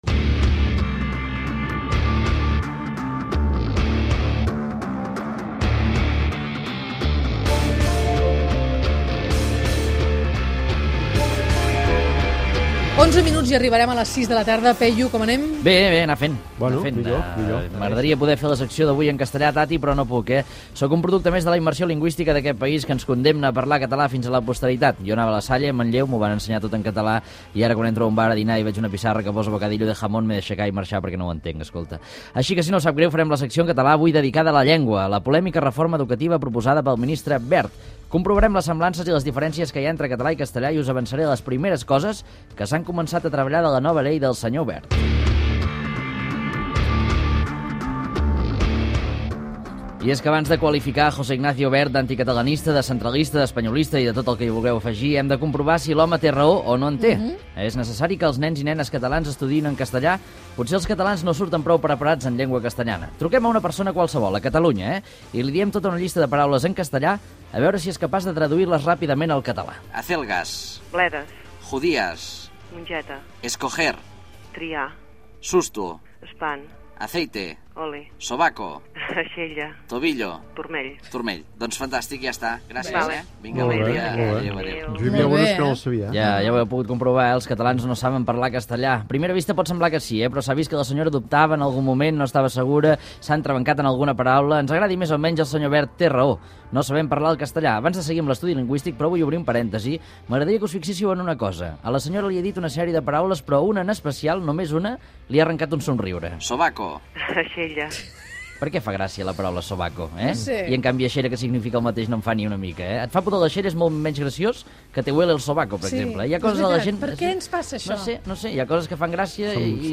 Col·laboració humorística de Peyu comentant la voluntat del ministre espanyol d'educació José Ignacio Wert d'acabar amb la immersió lingüística a Catalunya
Gènere radiofònic Entreteniment